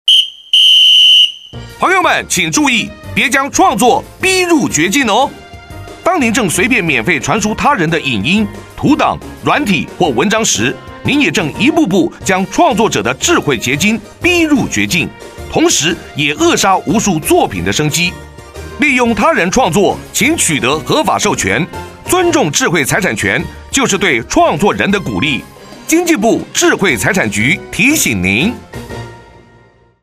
（二）運用全國各廣播電台播放保護著作權宣導廣告及「營業場所著作權篇」50秒宣導短劇等共計1,260檔次以上，全面建立民眾保護智慧財產權之正確認知。